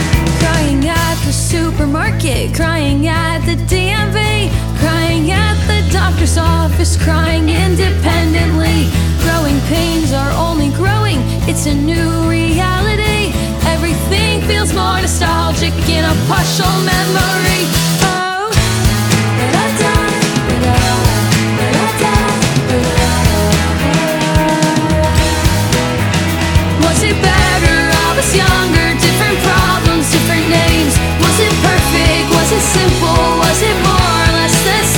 Alternative
2024-09-25 Жанр: Альтернатива Длительность